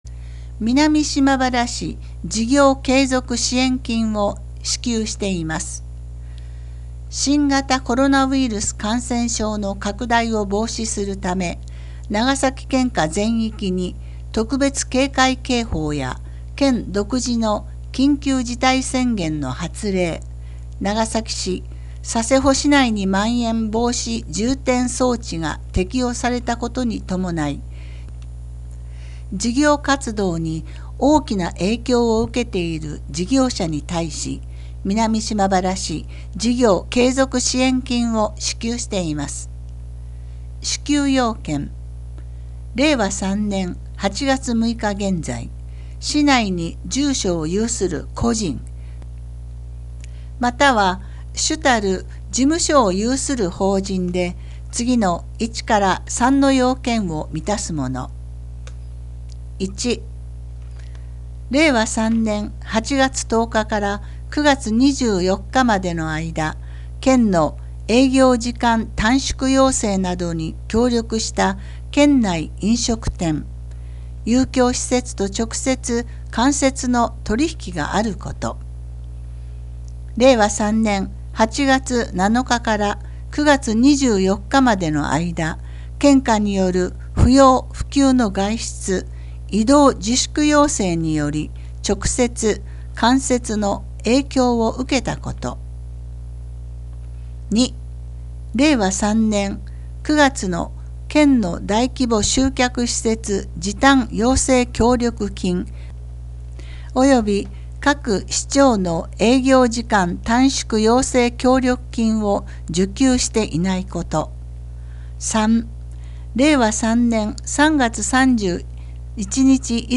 音訳（※声の広報紙）